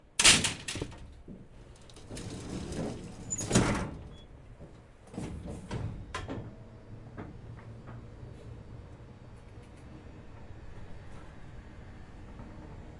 乔治皮博迪图书馆 " 电梯门关闭时发出吱吱声
描述：一个古老的图书馆电梯的门打开了吱吱声。
标签： 关闭 图书馆 电梯 现场记录
声道立体声